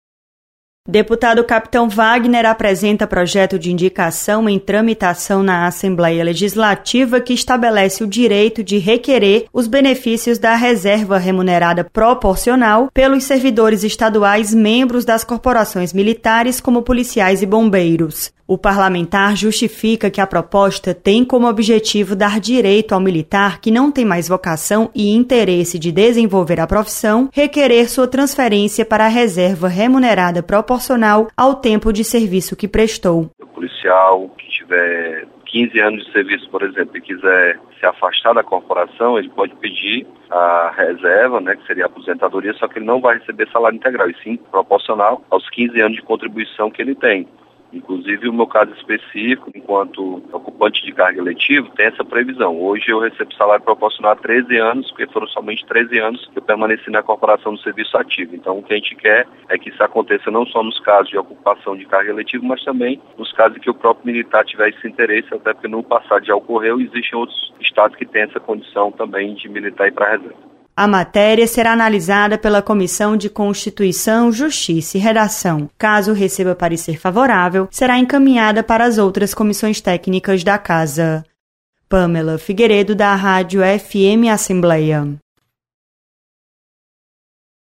Projeto revisa benefícios da reserva remunerada para policiais e bombeiros. Repórter